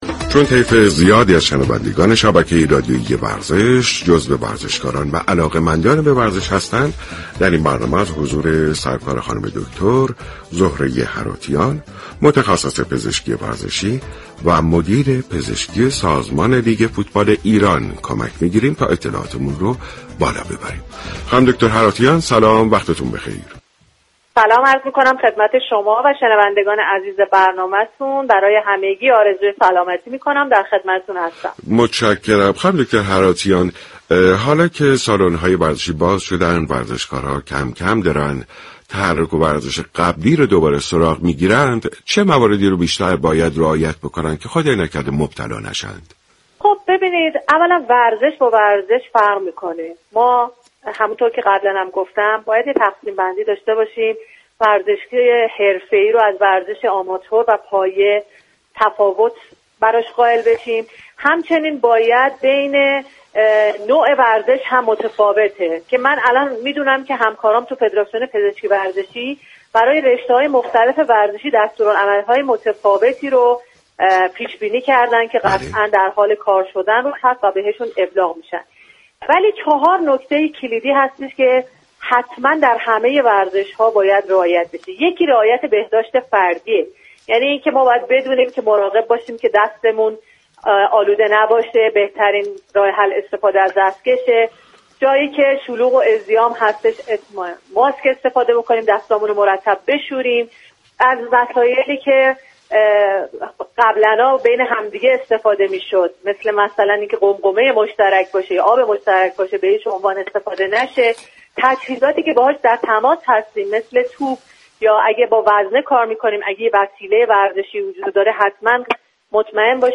شما می توانید از طریق فایل صوتی ذیل شنونده بخشی از برنامه سلامت باشیم رادیو ورزش كه شامل صحبت های این متخصص پزشكی ورزشی درباره كرونا است؛ باشید.